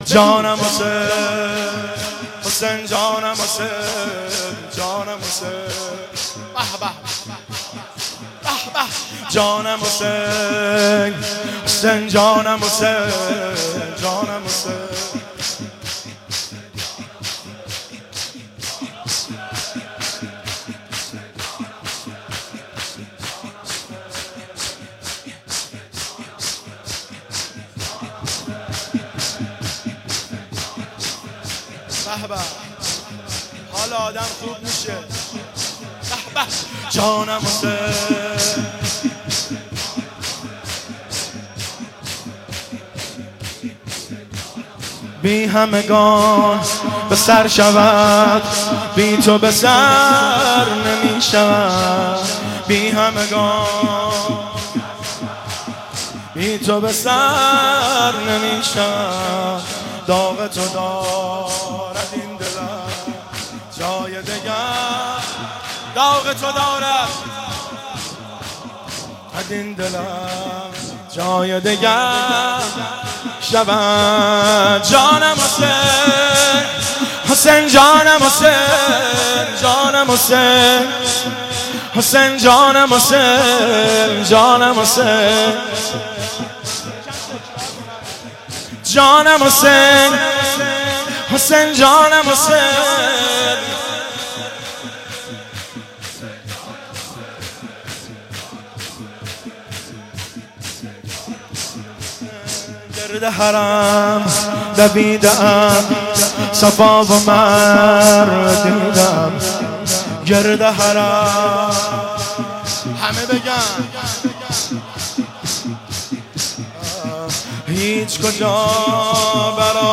نوحه و مداحی
پرستوی زخمی حیدر کجا میری - زمینه